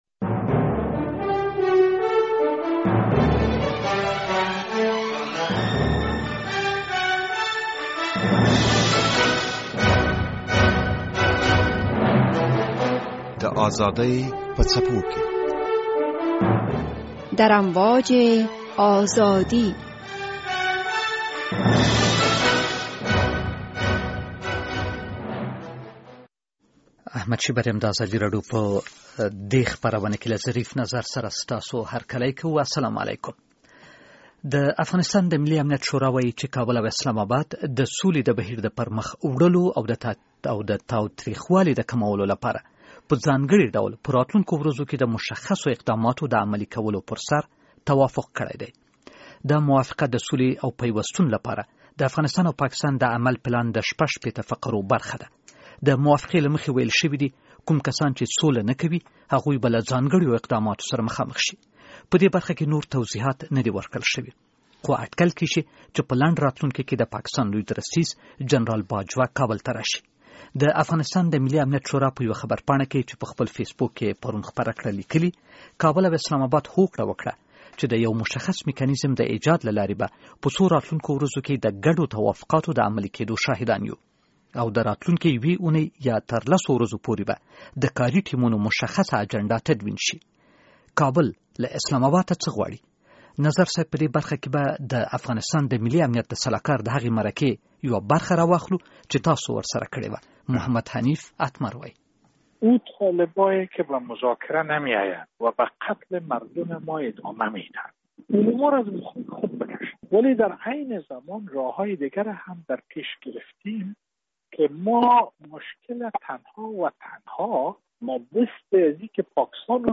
در برنامه این هفته در امواج آزادی که به روز پنج‌شنبه به صورت زنده به نشر رسید در مورد سفیر اخیر مشاور امنیت ملی افغانستان به پاکستان و توافقاتی که ...